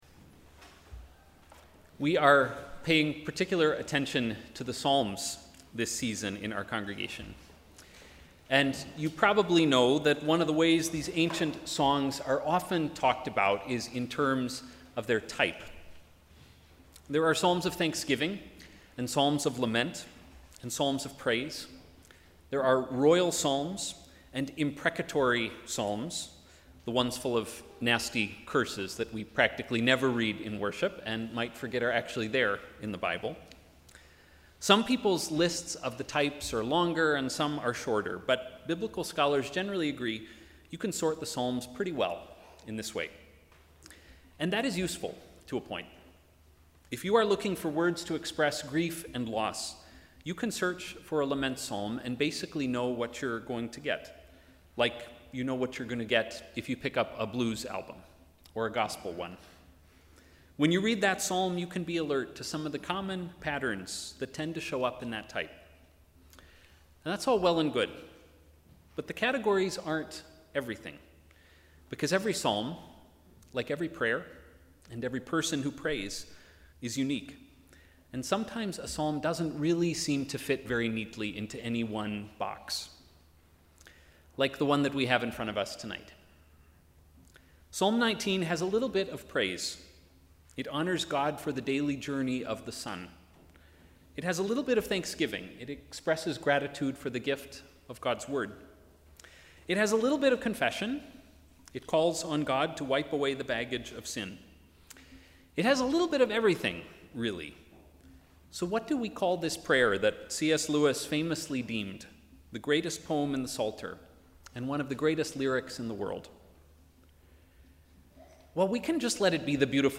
Sermon: ‘An invitation to wonder’